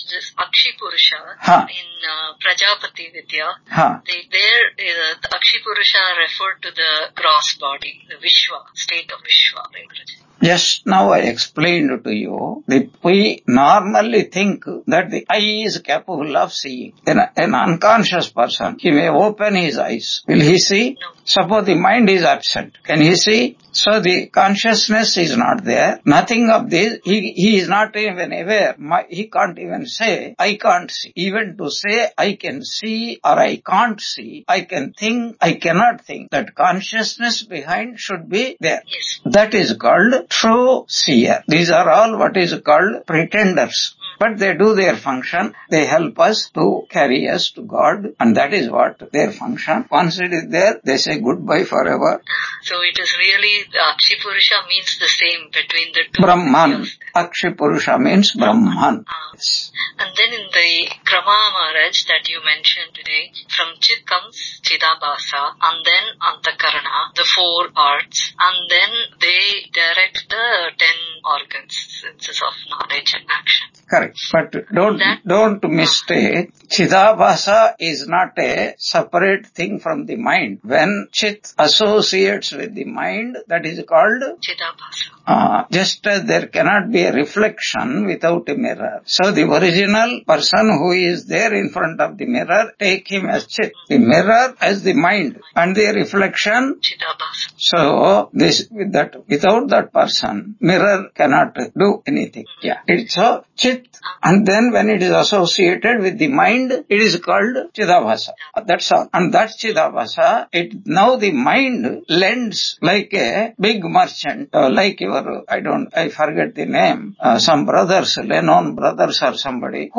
Chandogya Upanishad 4.14-15 Lecture 145 on 11 October 2025 Q&A